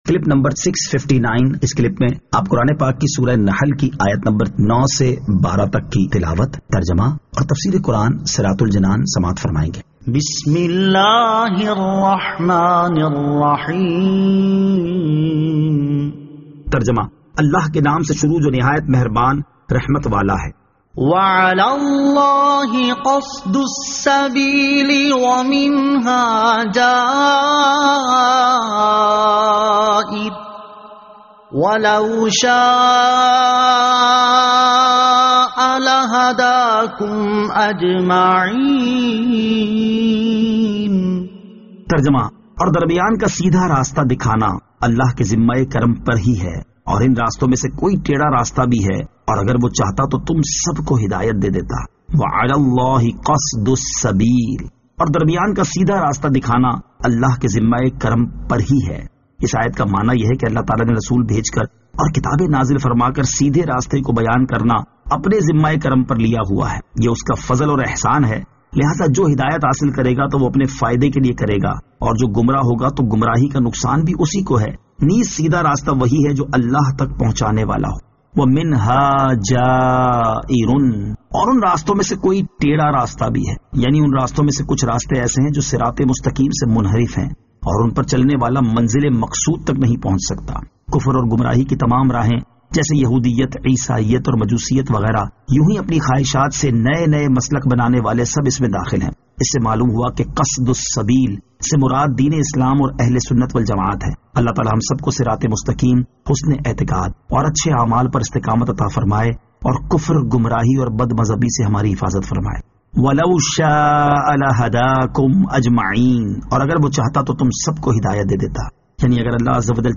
Surah An-Nahl Ayat 09 To 12 Tilawat , Tarjama , Tafseer